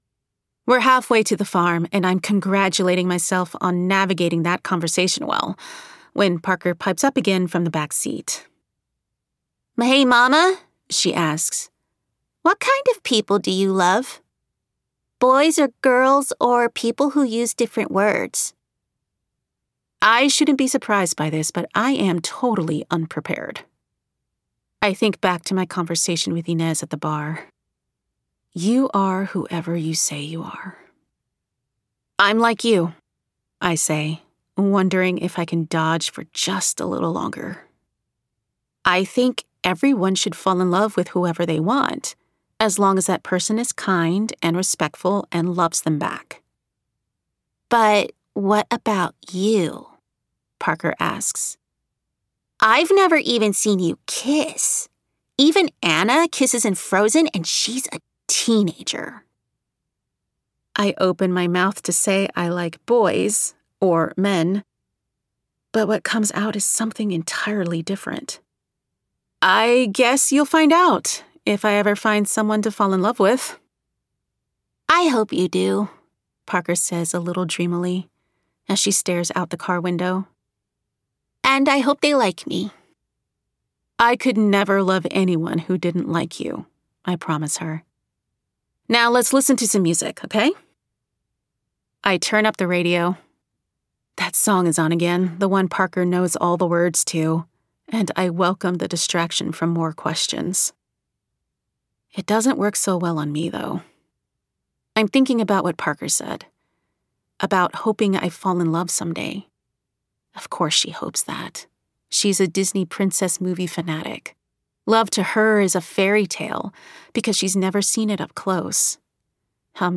Bold and cinematic in their expression with a knack for comedic timing and a voice described as earthy, vulnerable, and nuanced